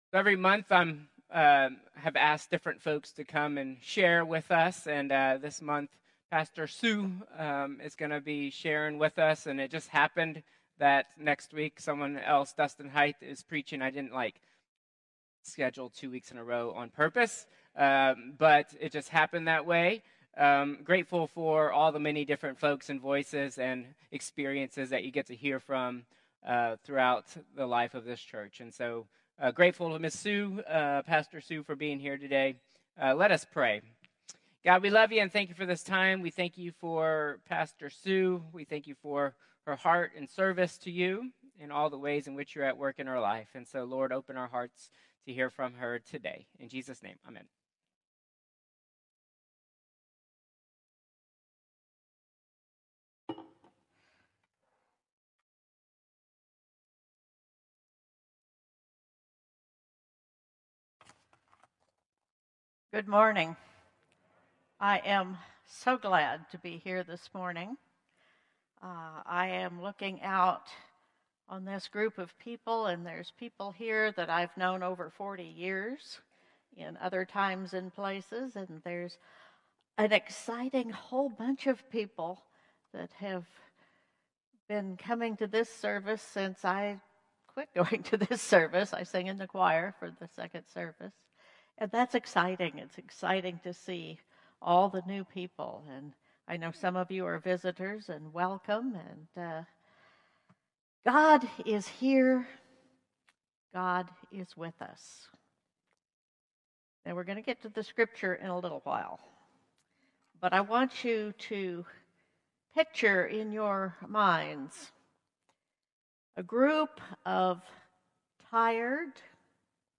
Contemporary Service 5/25/2025